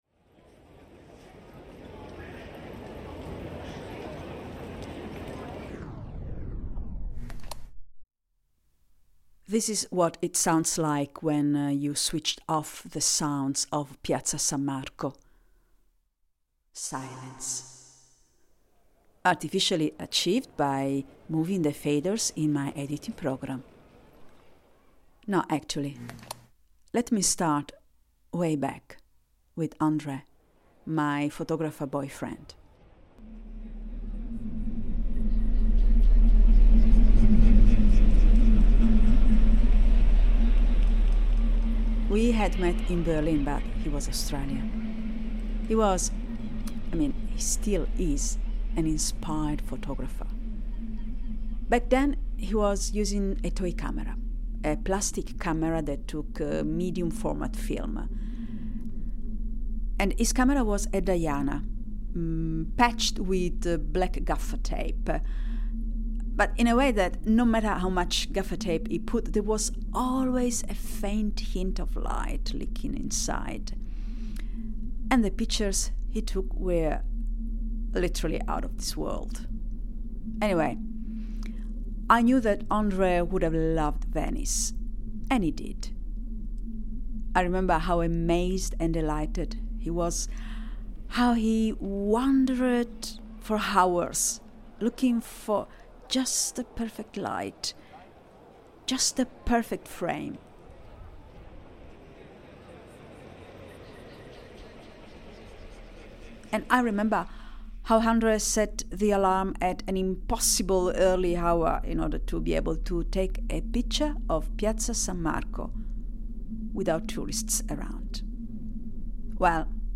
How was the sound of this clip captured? Piazza San Marco, Venice reimagined